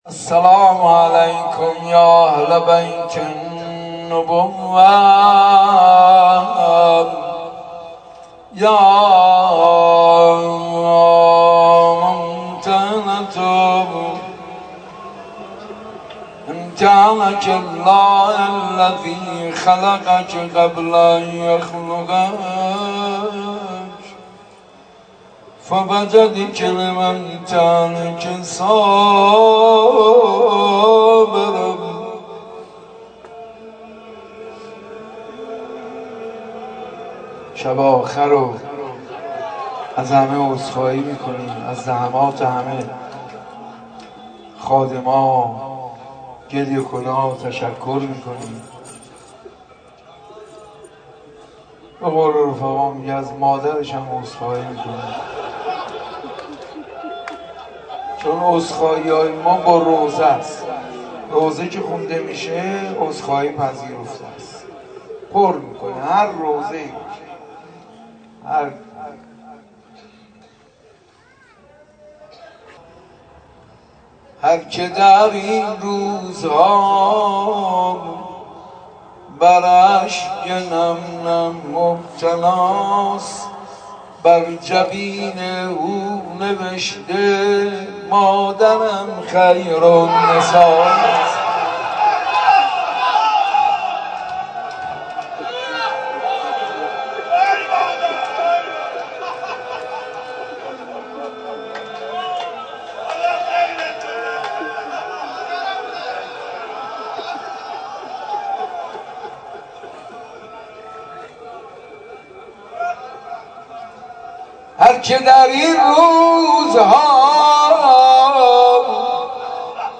آخرین شب از مراسم اقامه عزای سید الشهدا در بیت الزهرا
عقیق: حاج منصور ارضی در شب آخر مراسم، با اشعار خود اتفاقاتی که برای مادر سادات در مدینه افتاده را به جریان کربلا مرتبط کرد. روضه خوانی